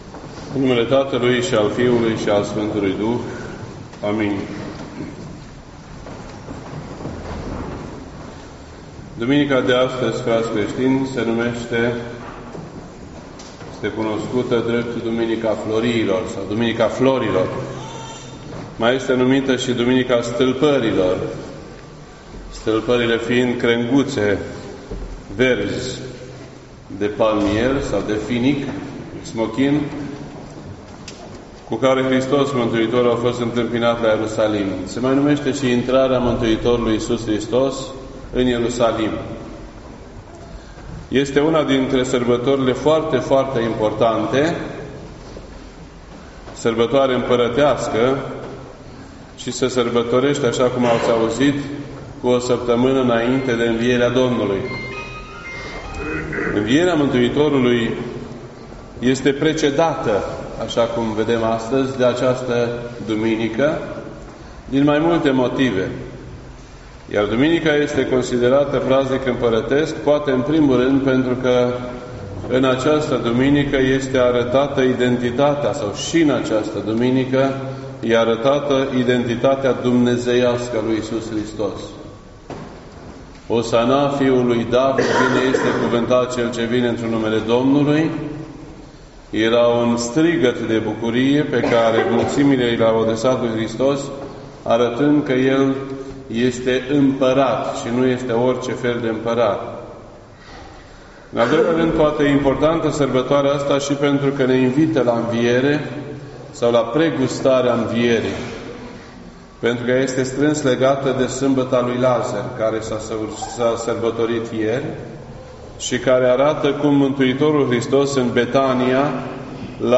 Predici ortodoxe in format audio